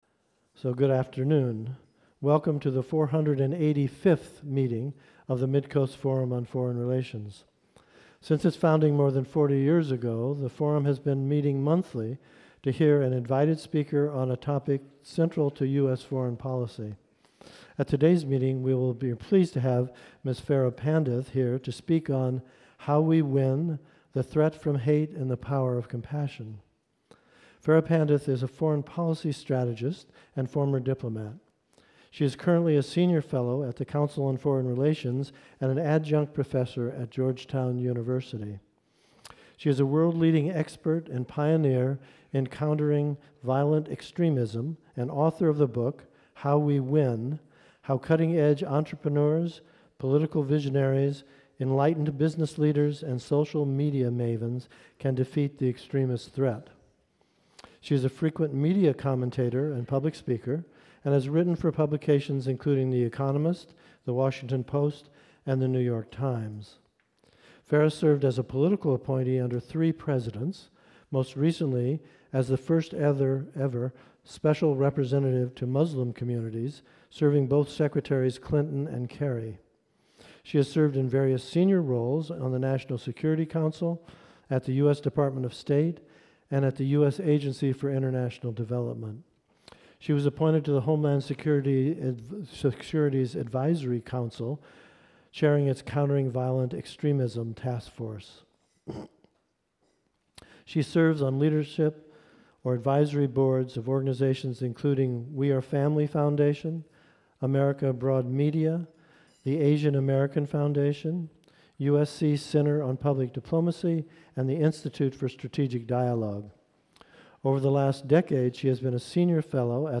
The Midcoast Forum on Foreign Relations recently hosted a talk by Farah Pandith, a foreign policy strategist and former diplomat on the threat from hate and the power of compassion. Listen to the talk.